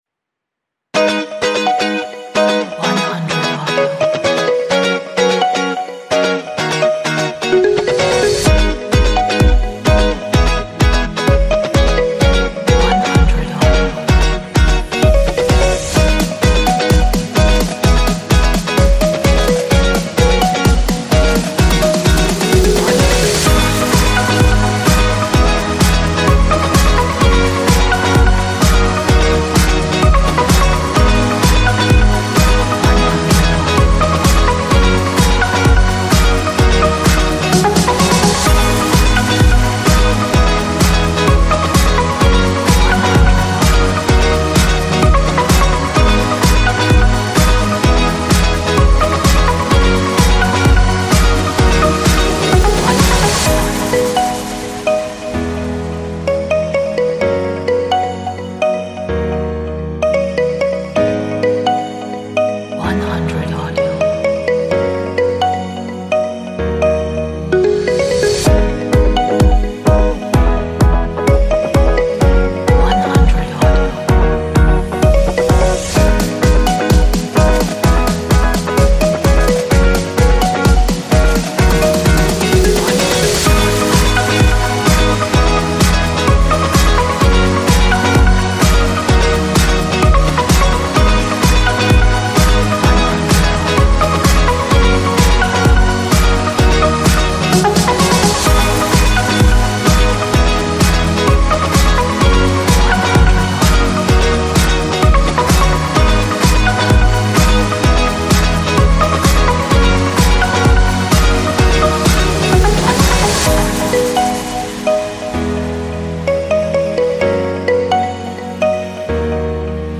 Optimistic Pop Mood track for your projects.